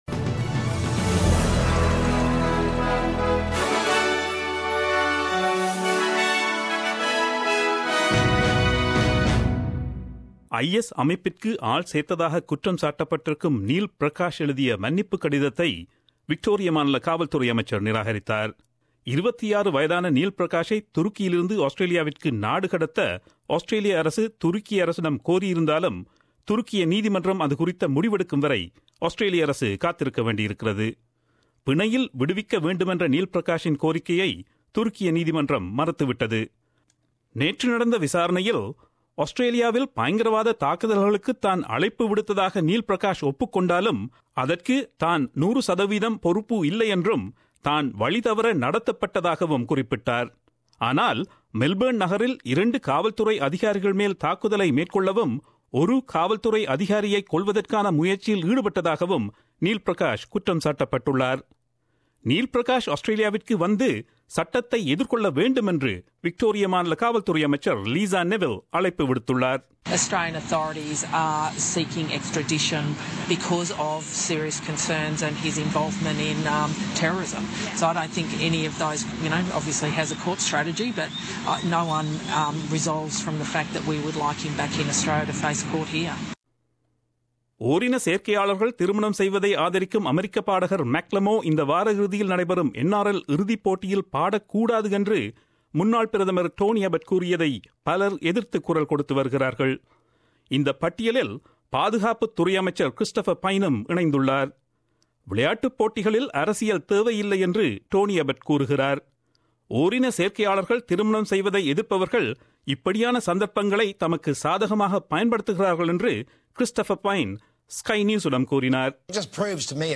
Australian news bulletin aired on Friday 29 September 2017 at 8pm.